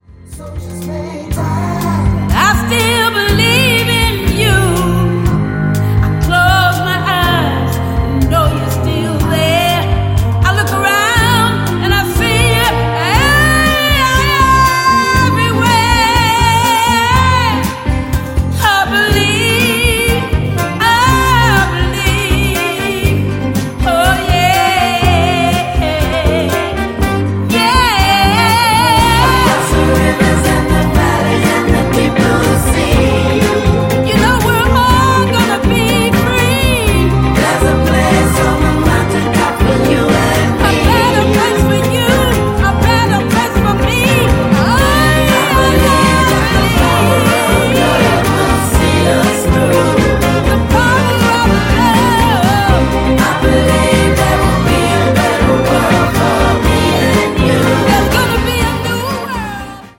Soul-pop